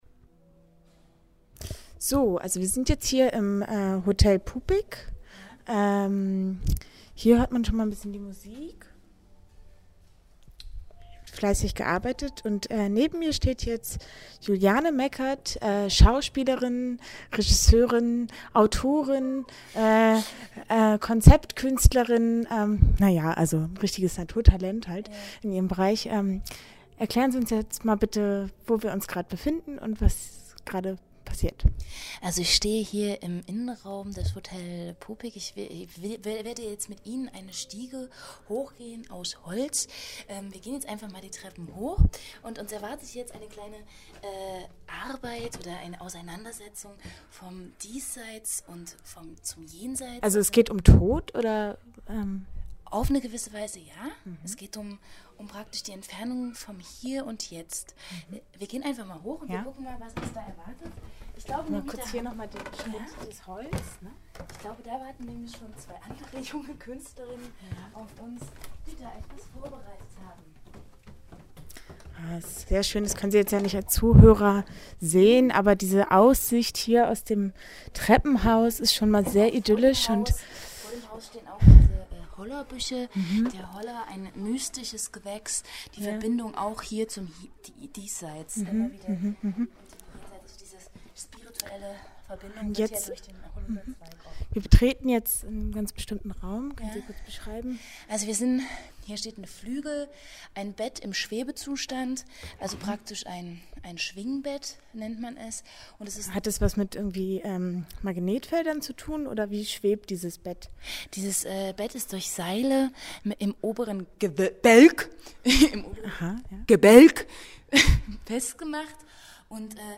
Interview mit wilde pferde